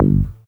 BAS_Sampl-Bass.wav